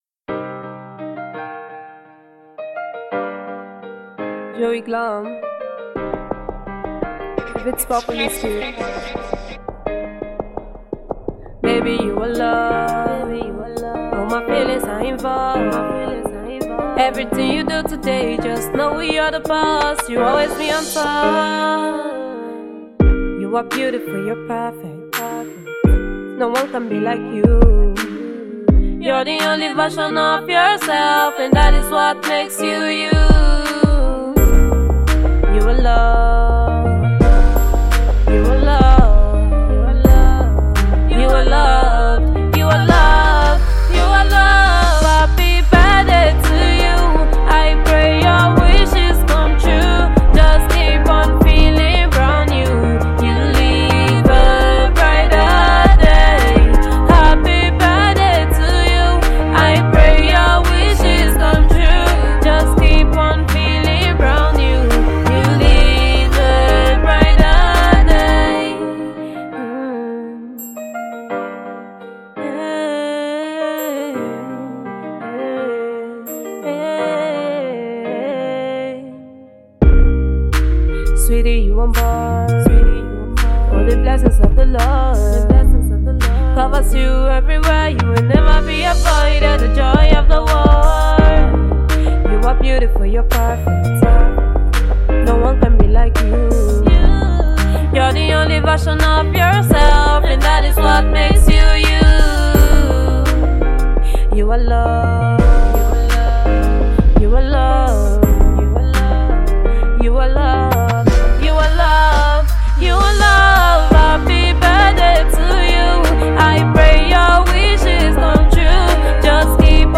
Afrobeat